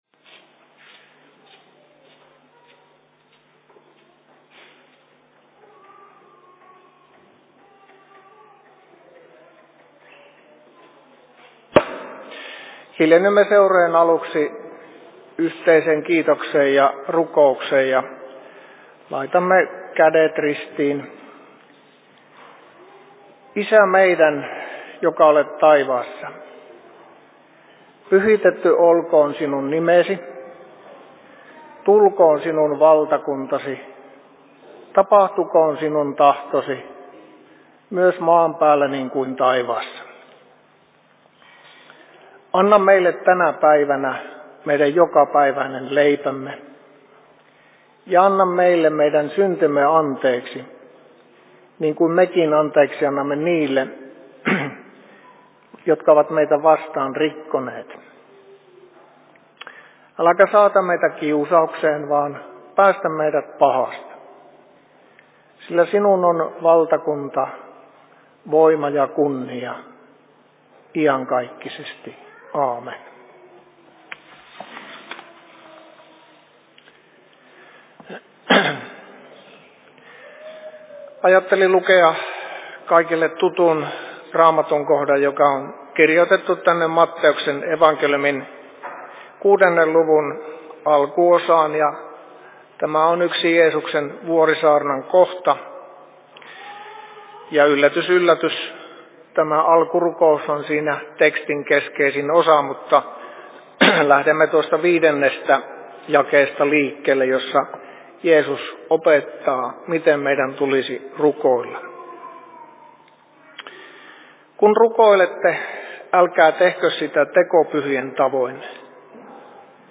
Seurapuhe Sievin RY:llä 26.02.2023 18.00
Paikka: Rauhanyhdistys Sievi